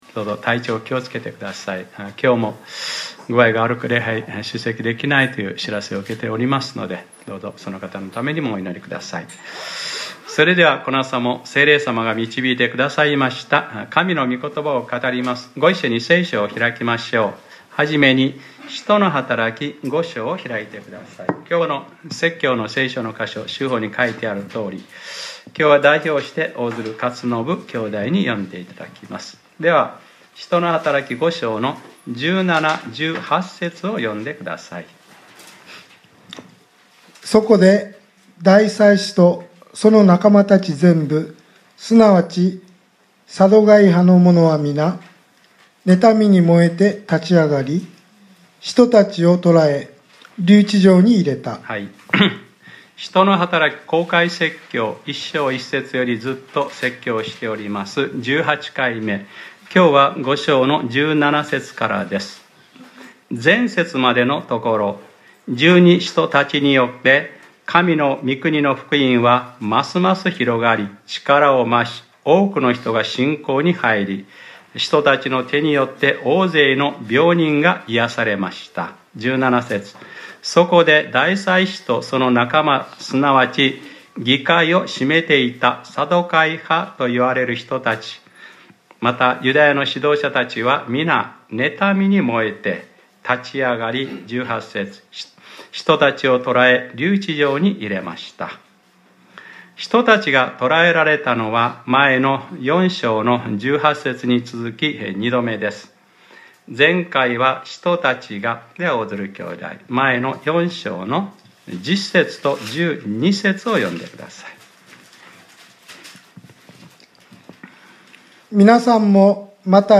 2018年02月11日（日）礼拝説教『使徒ｰ18：プレロマ』 | クライストチャーチ久留米教会